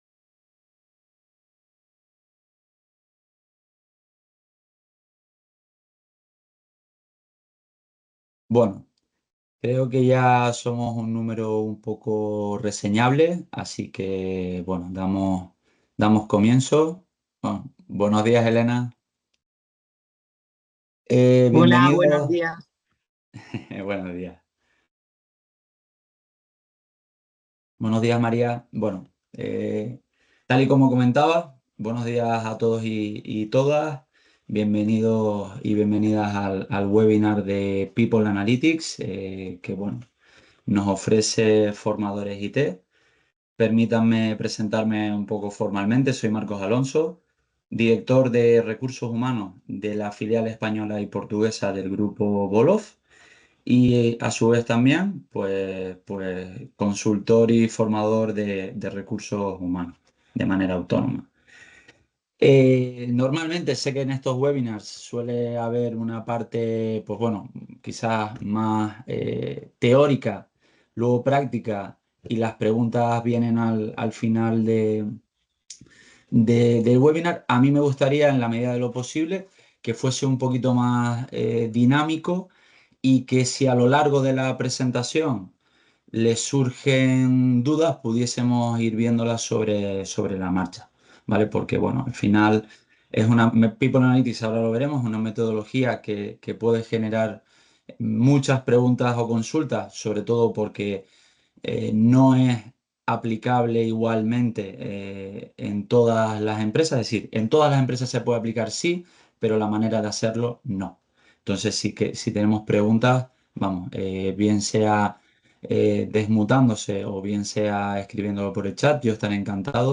Webinar: Por qué implementar People Analytics en tu empresa